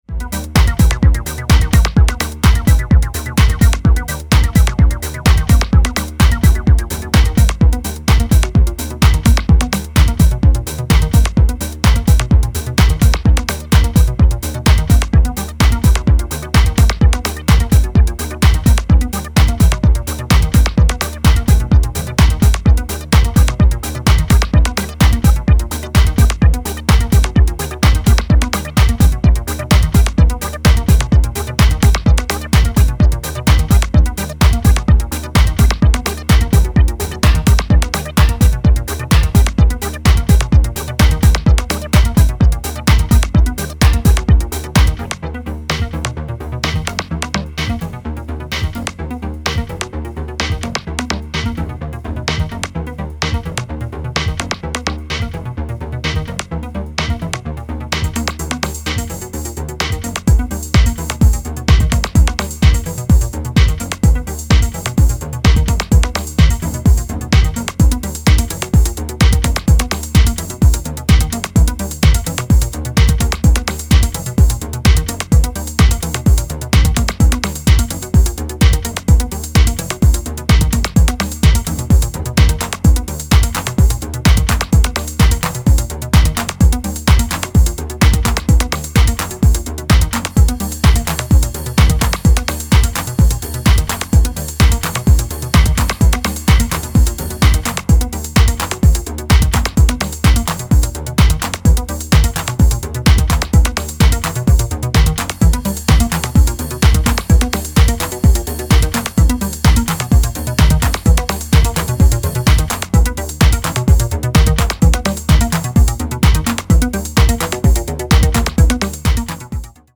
即効性抜群のアシッド・ミニマル